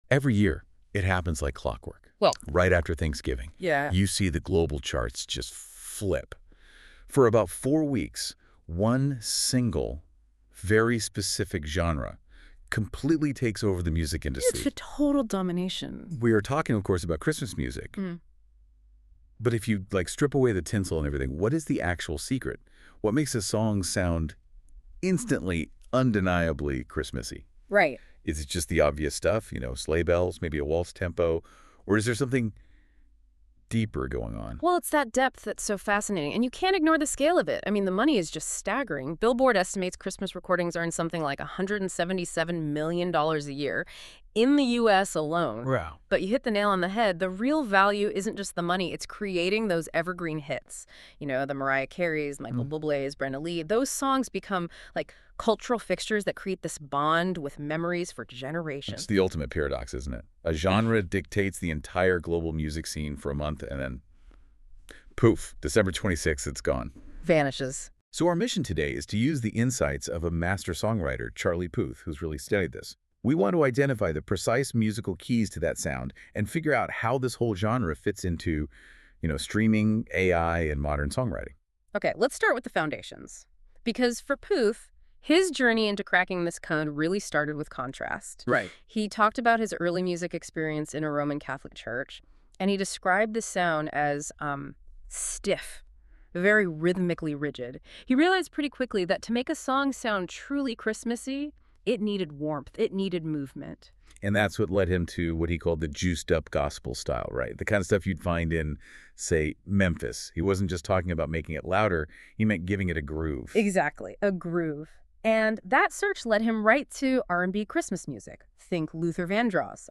In this Billboard podcast interview